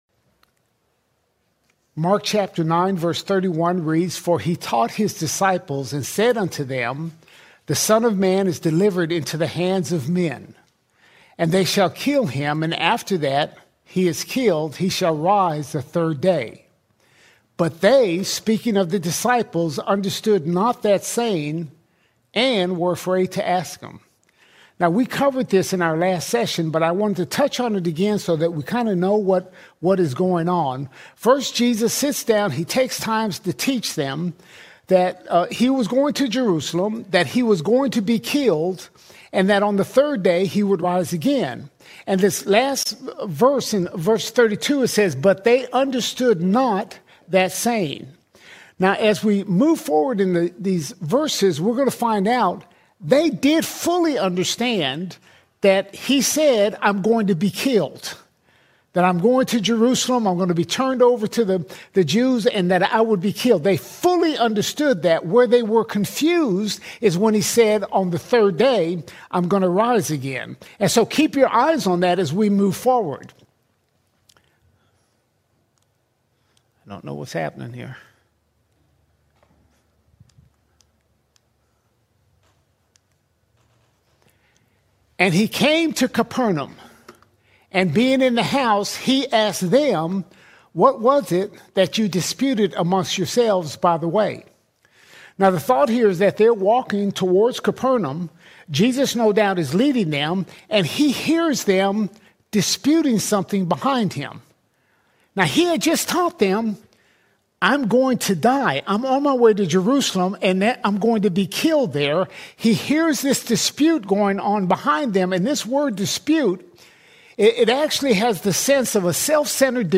5 February 2026 Series: Gospel of Mark All Sermons Mark 9:31 - 10:14 Mark 9:31 – 10:14 Jesus teaches His disciples about the cross, true greatness, and the heart of God’s kingdom.